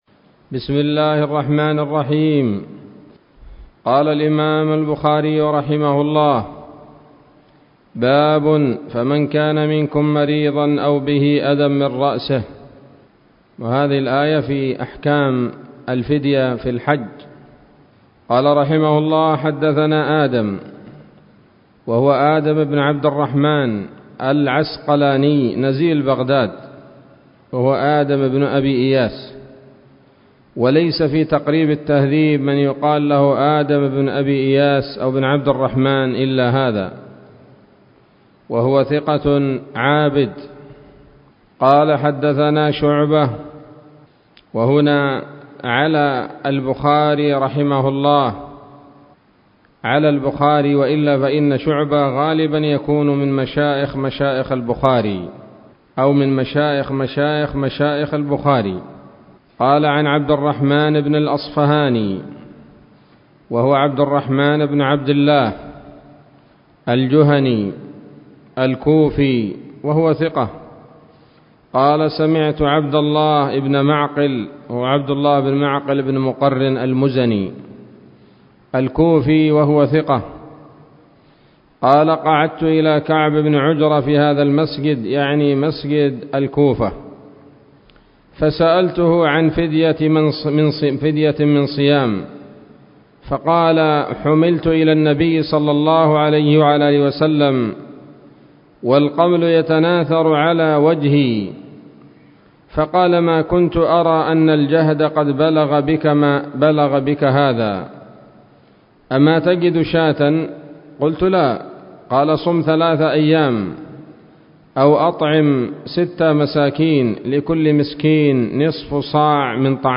الدرس السابع والعشرون من كتاب التفسير من صحيح الإمام البخاري